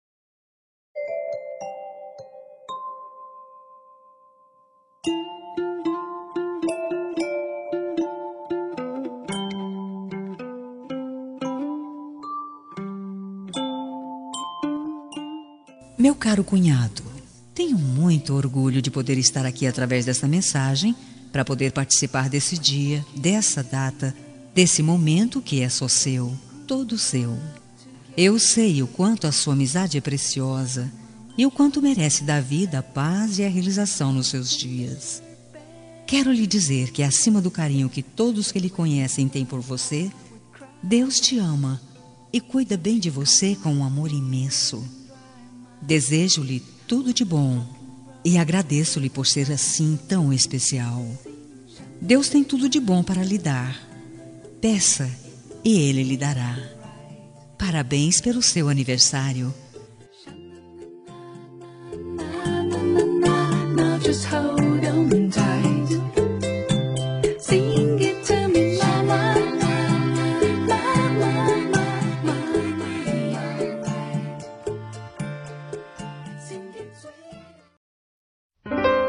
Aniversário de Cunhado – Voz Feminino – Cód: 5224